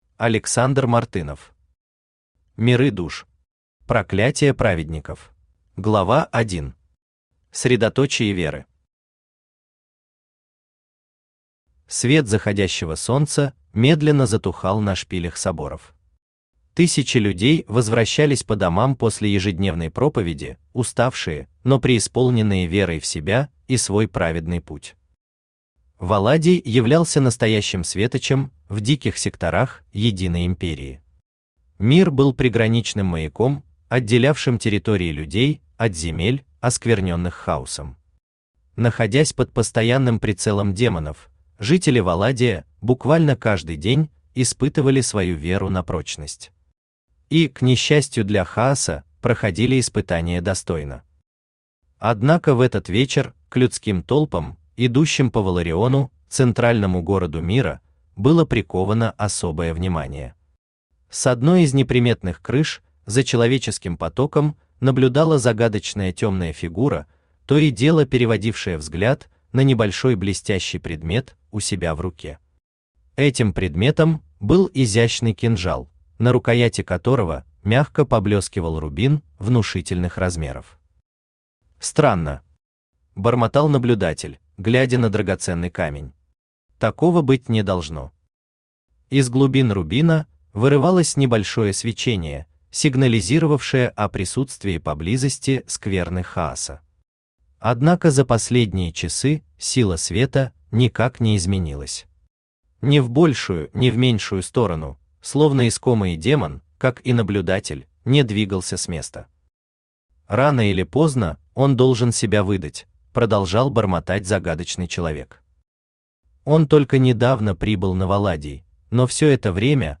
Проклятие праведников Автор Александр Мартынов Читает аудиокнигу Авточтец ЛитРес.